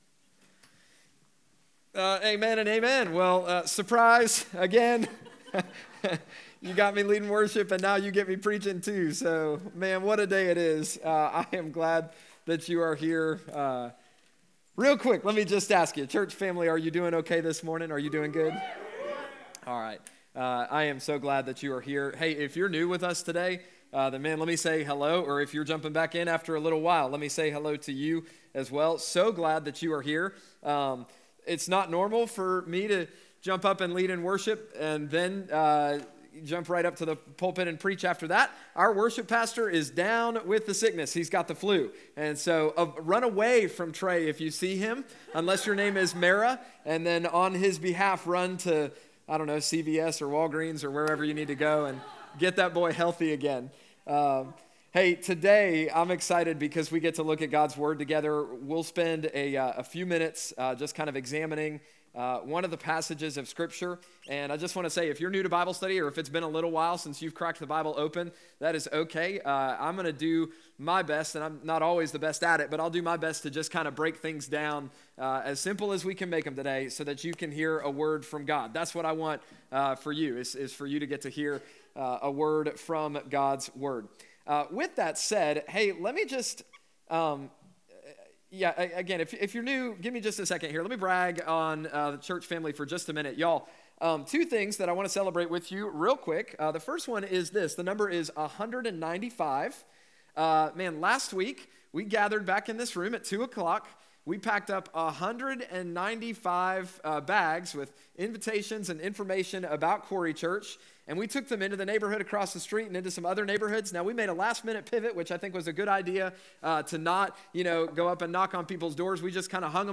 Contact Us New Worship Center Connect Ministries Events Watch QC Live Sermons Give 2.16.2025 - Hold Fast To The Church February 16, 2025